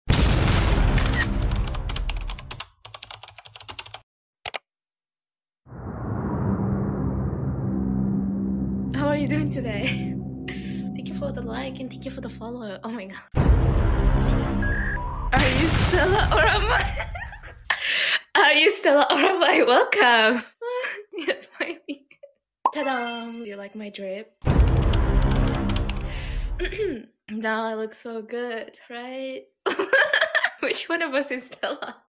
Silly goofy mood